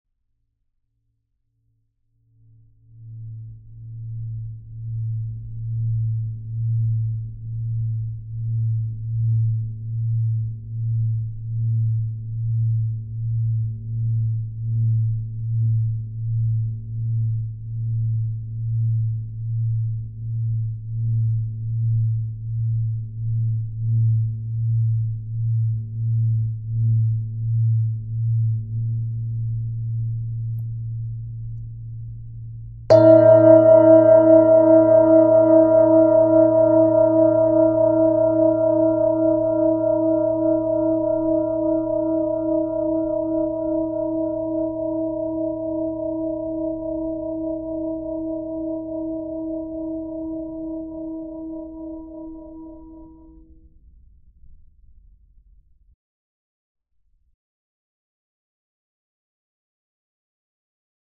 シンギングボウルは、特有の音や振動を利用して、身体や心の健康に良い影響を与えるツールです。